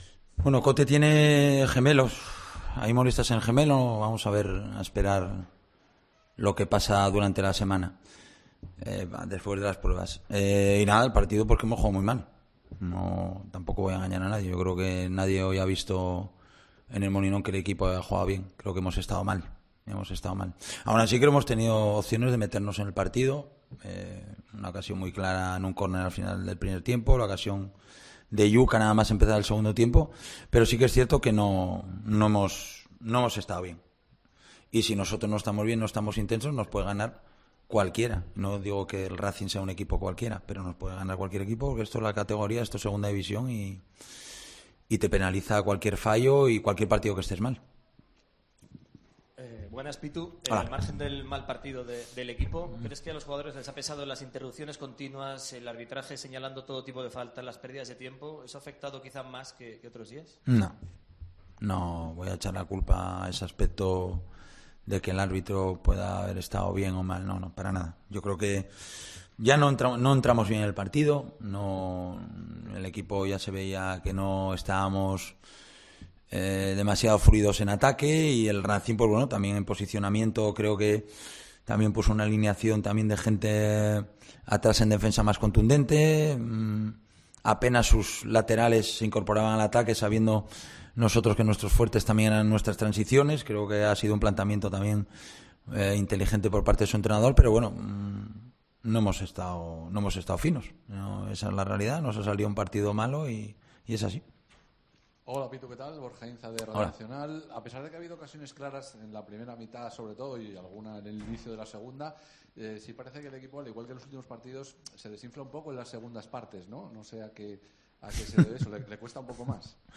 Rueda de prensa Abelardo (post Racing)